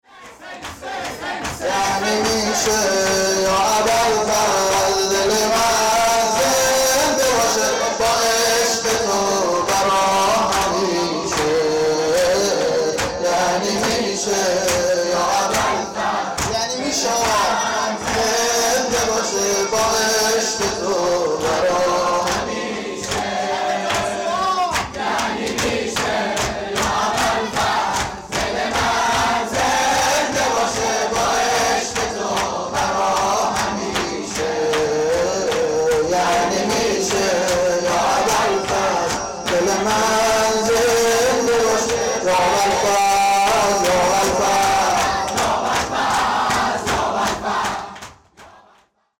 • ذکرانتهایی هرشب هیئت